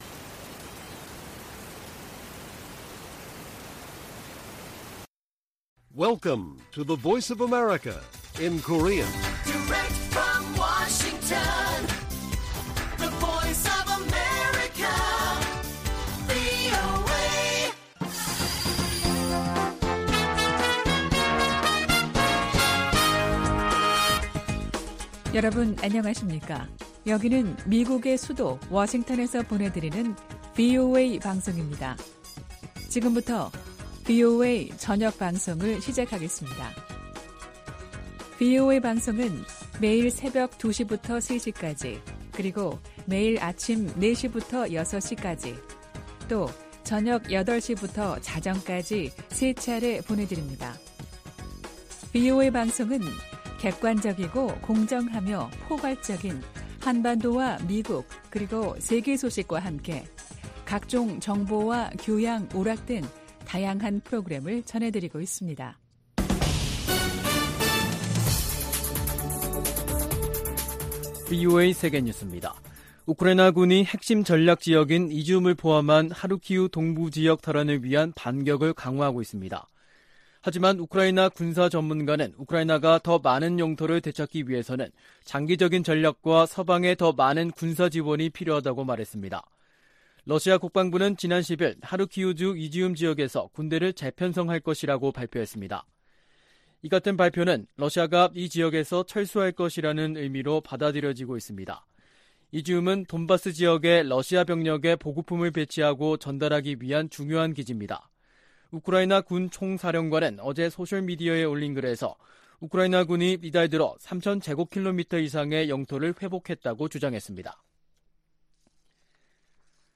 VOA 한국어 간판 뉴스 프로그램 '뉴스 투데이', 2022년 9월 12일 1부 방송입니다. 북한이 경제난 속 ‘핵 법제화’를 강행하는 것은 중러와의 3각 밀착이 뒷받침을 하고 있다는 분석이 나오고 있습니다.